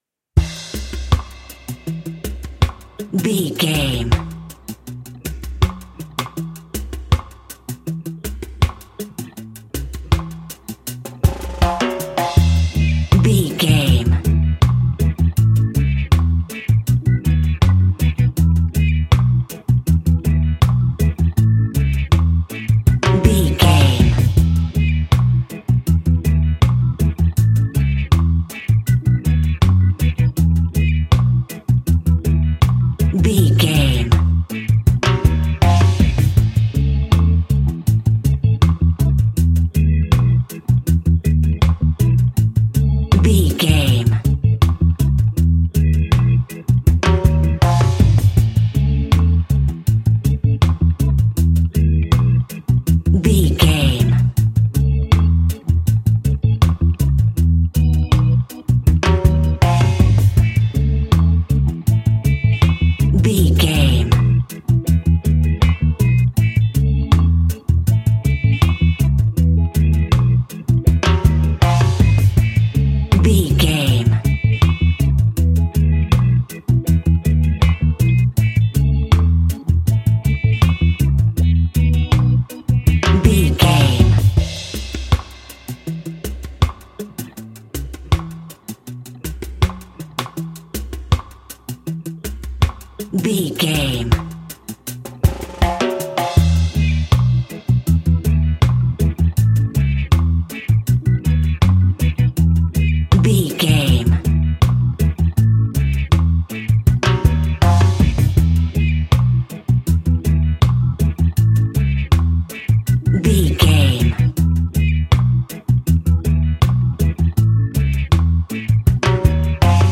Classic reggae music with that skank bounce reggae feeling.
Ionian/Major
instrumentals
laid back
chilled
off beat
drums
skank guitar
hammond organ
percussion
horns